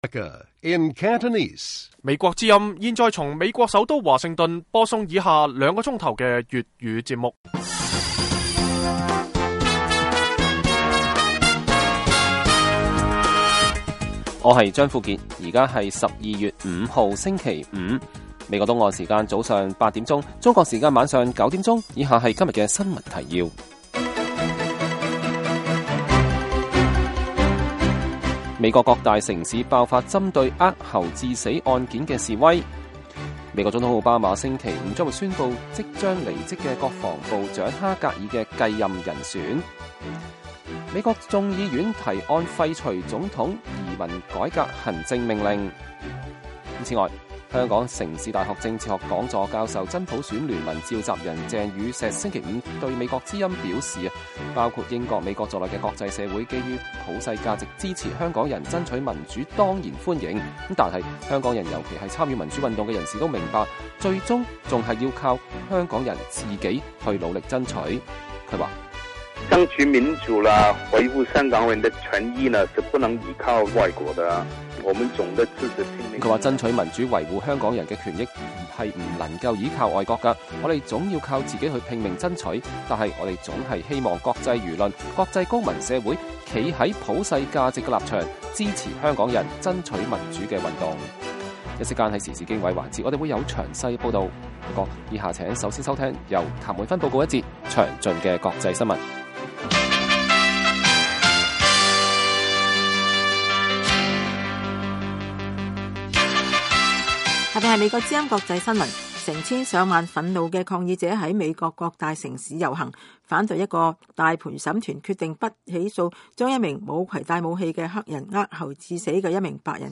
粵語新聞 晚上9-10點
每晚 9點至10點 (1300-1400 UTC)粵語廣播，內容包括簡要新聞、記者報導和簡短專題。